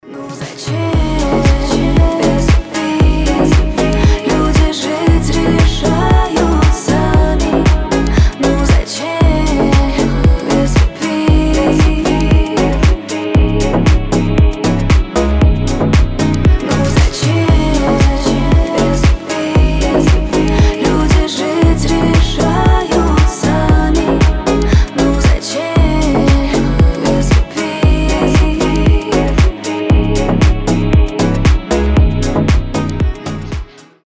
• Качество: 320, Stereo
красивые
женский голос
нежные